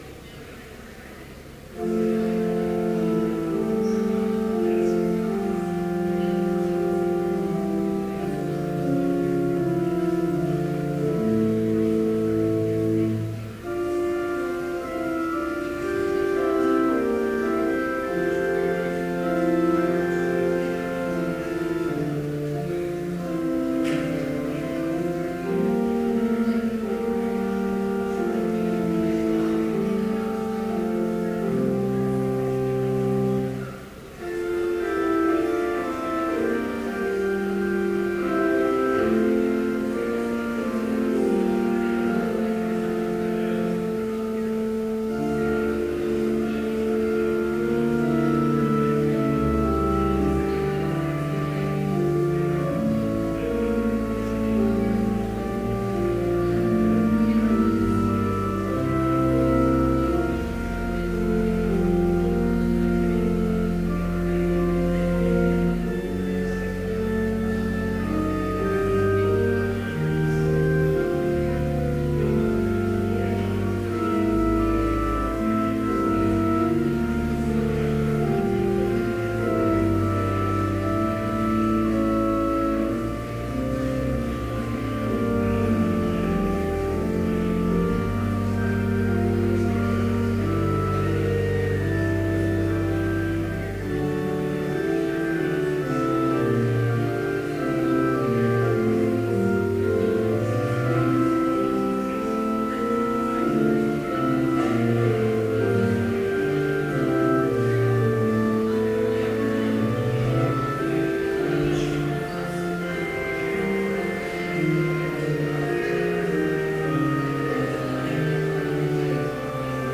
Complete service audio for Chapel - February 18, 2014